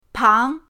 pang2.mp3